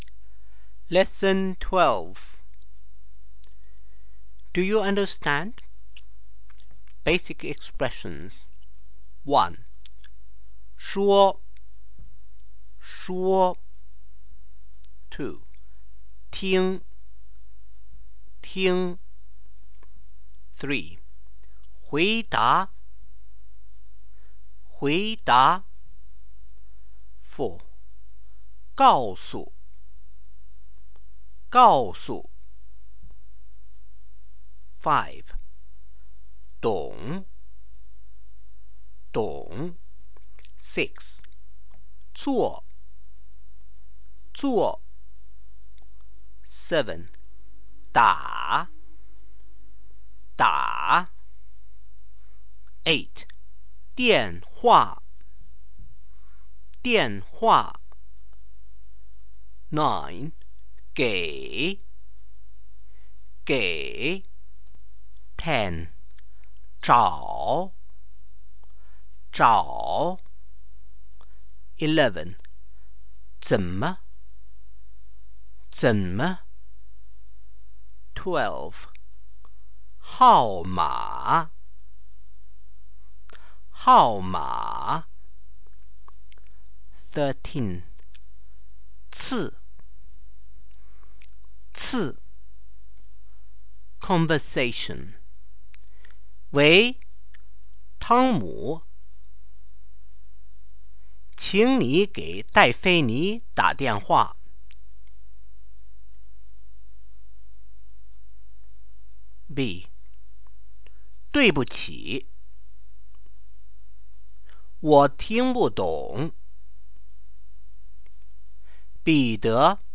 Tape version
Part three: conversation